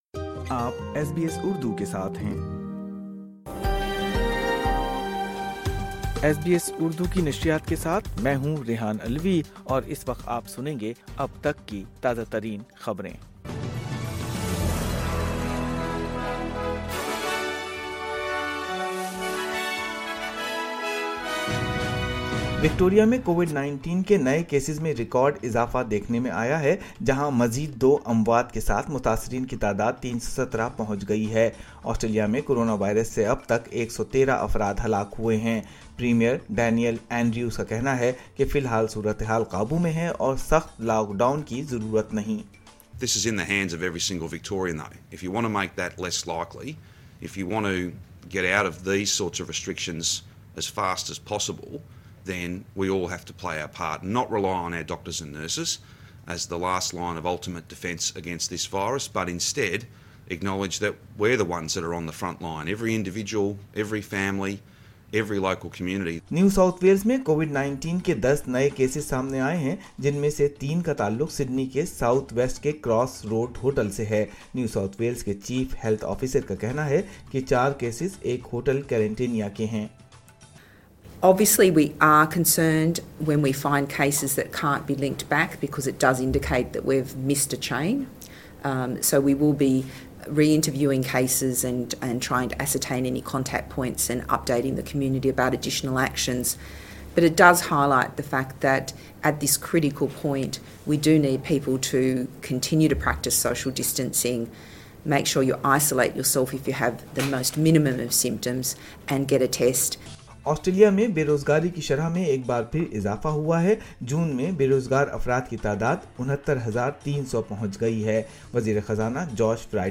اردو خبریں 16 جولائی 2020